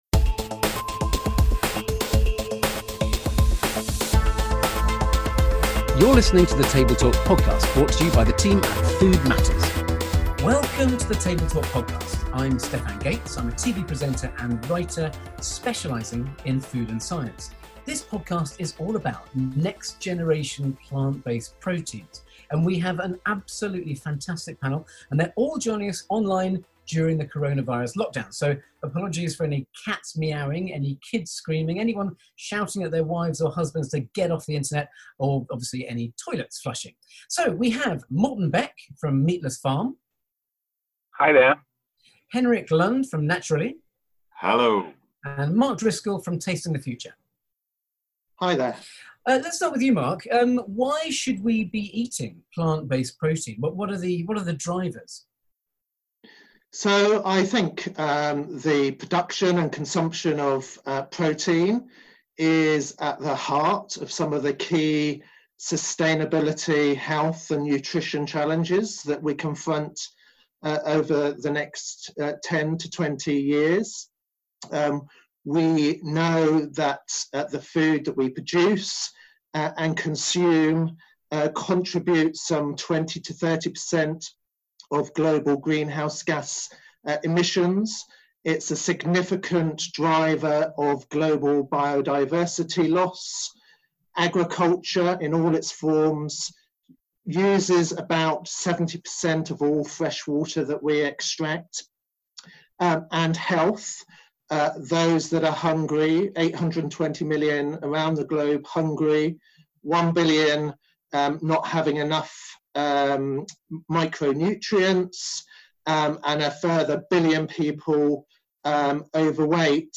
In a fascinating and lively discussion we join three experts in the sector from The Meatless Farm Company, Natural, and Tasting the Future.